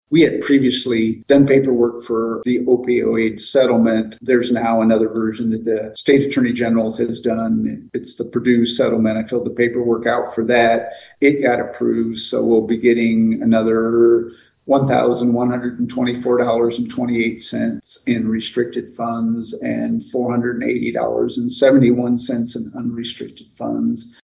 Monday night, LaGrange Town Manager Mark Eagleson provided an update to the Town Council on the settlement funds.